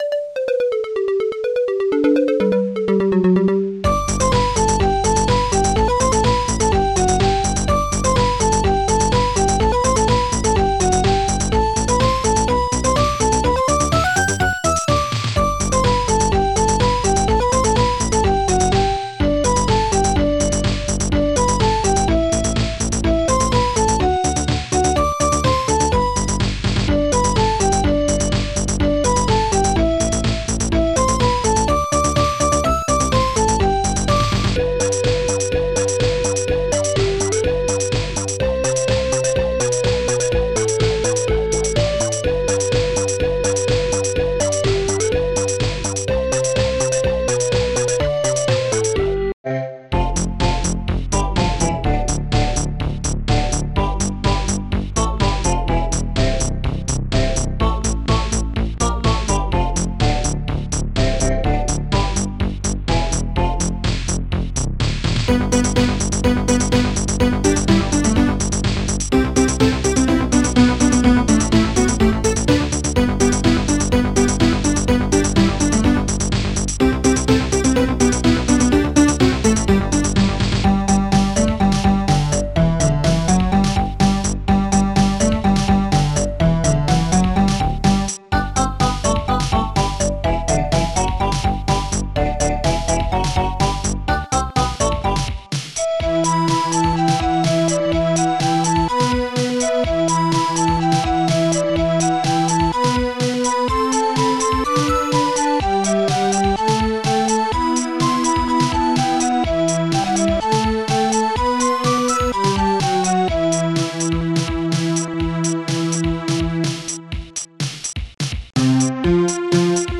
BASSDRUM2 st-06:Snare7 HIHAT1 DREAMBELLS BLOWER st-03:acidbass stabs hoh bubblebass' strings BONGOHI